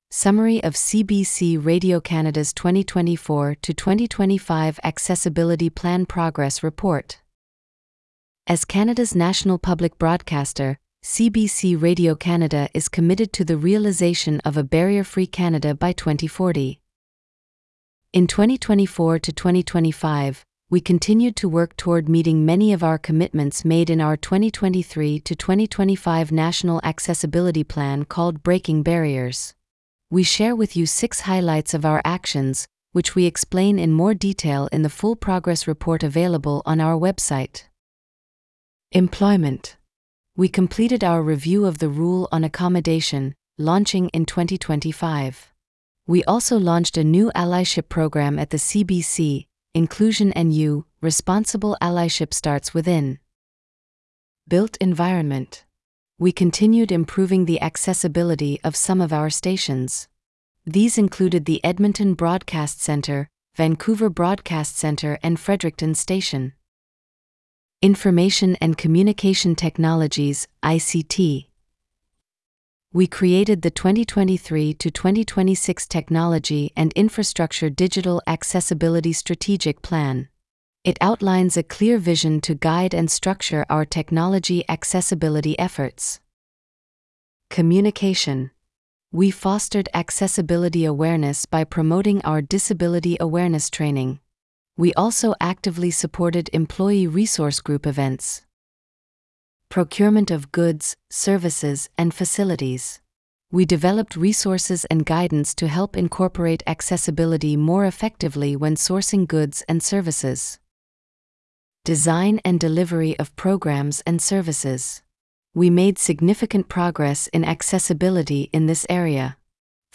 summary-progress-report-audio-ai-en.wav